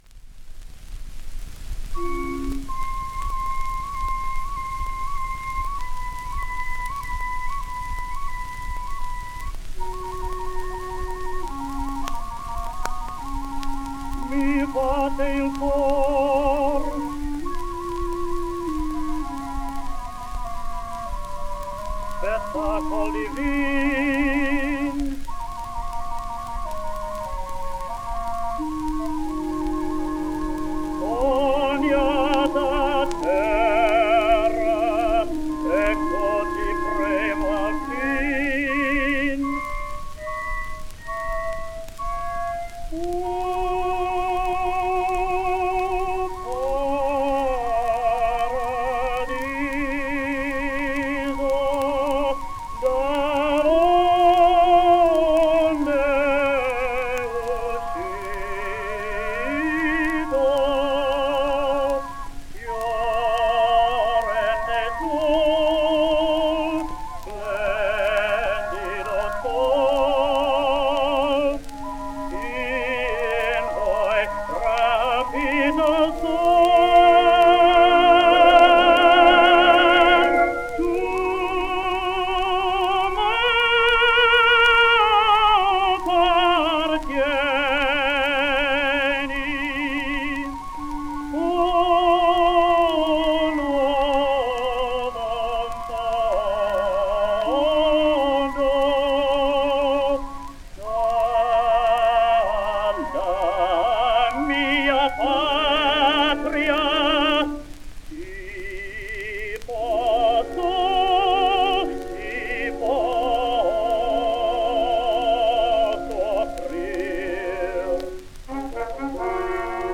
He became a well-reputed concert and oratorio singer, and a popular recording artist for Victor; he was also first tenor of the Orpheus Quartet (with Harry Macdonough, Reinald Werrenrath and William F. Hooley).